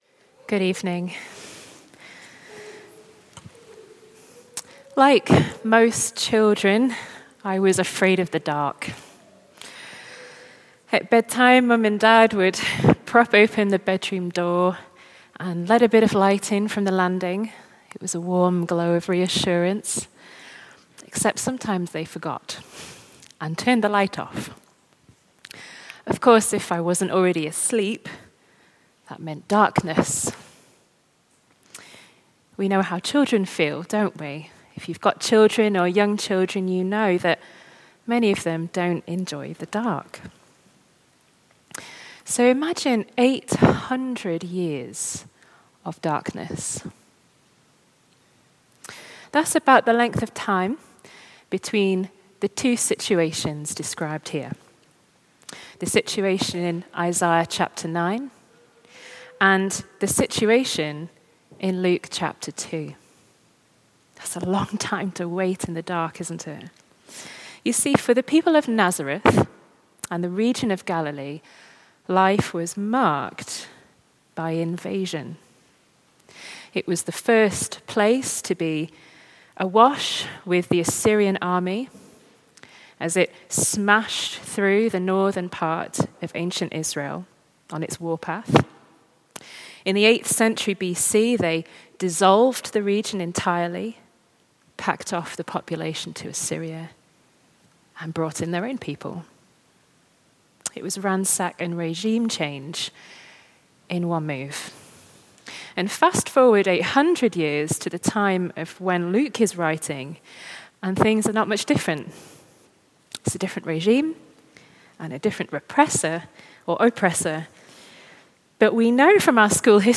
From Service: "Special service"
This is a talk for the "Blue Christmas" service picking up on some of the themes from this quieter & more contemplative style of Christmas service.